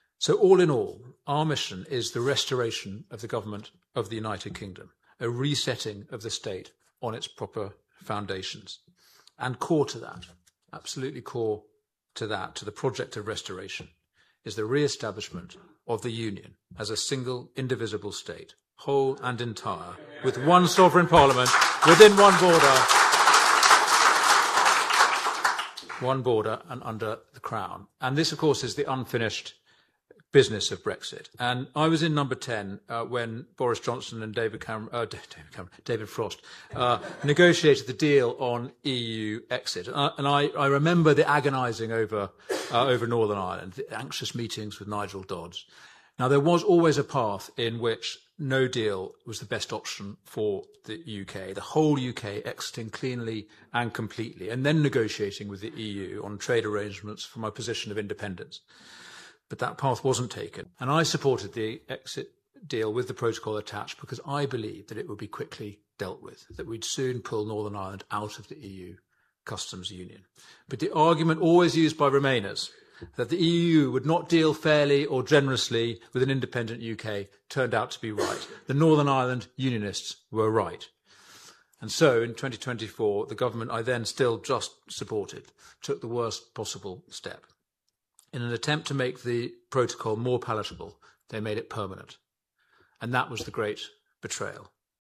Speaking at a Traditional Unionist Voice conference in Cookstown, he said the situation is unacceptable and must be addressed.
He told the event that Reform UK wants a new deal with the EU that restores full UK sovereignty: